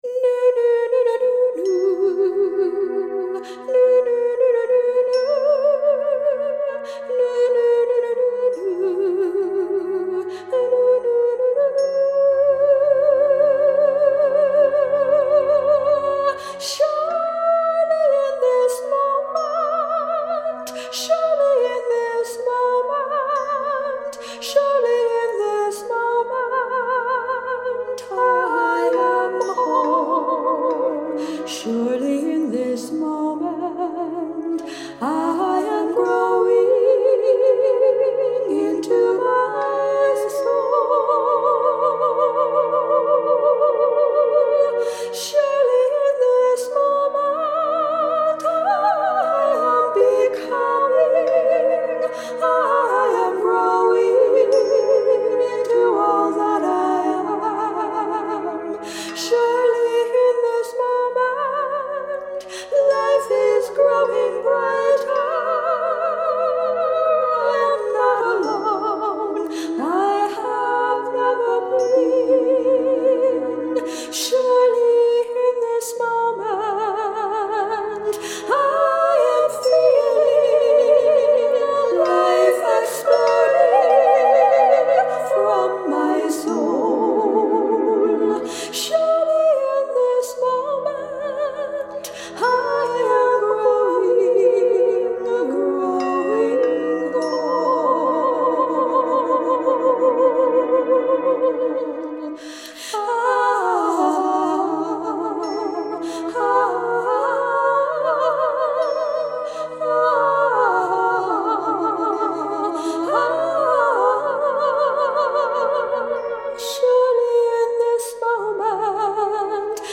Vocals and tuning forks on quartz crystals
Quartz crystal bowl